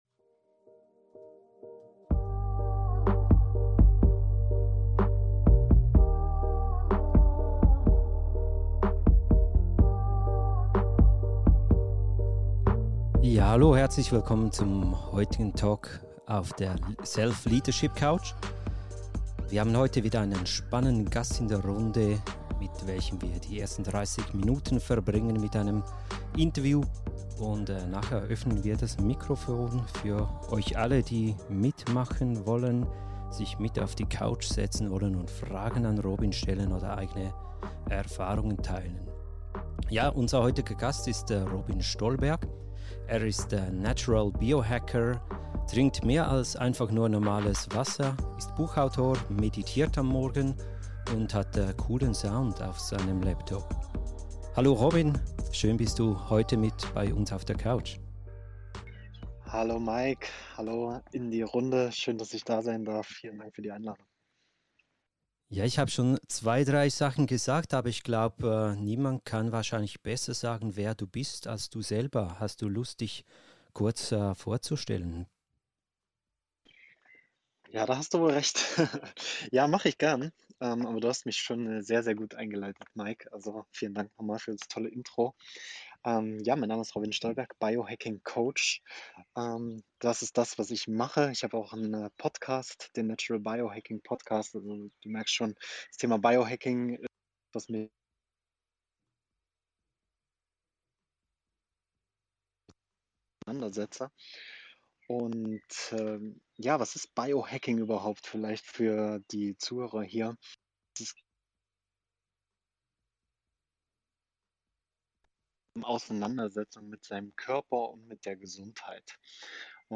Dieser Podcast wurde live auf Clubhouse aufgezeichnet. Wir bitten die kurzen Funklöcher/Unterbrücke am Anfang zu entschuldigen.